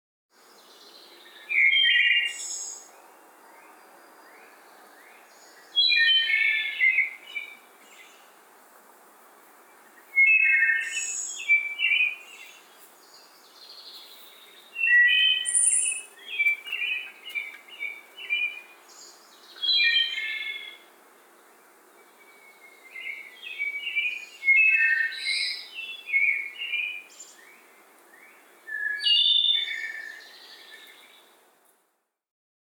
The Wood Thrush Sings
I knew its song immediately, the distinctive two part harmony it sings through its Y-shaped syrinx (voice box).
Here is a clip of his song that I captured a few mornings ago.
woodthrushwp.mp3